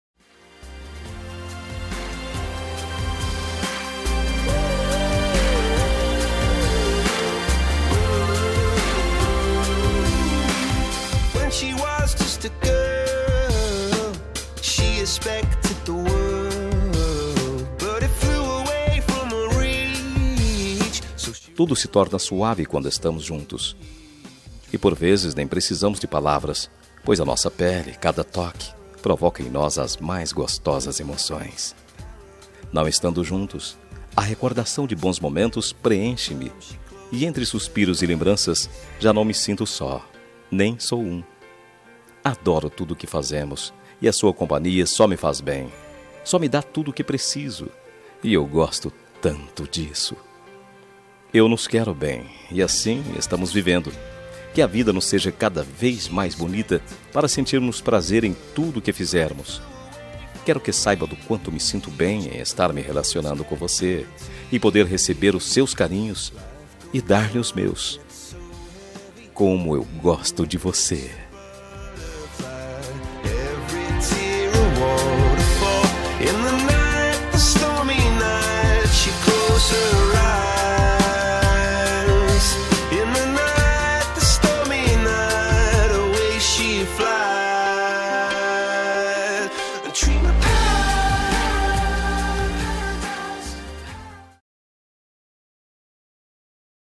Telemensagem Ficante – Voz Masculina – Cód: 5455